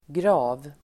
Uttal: [gra:v]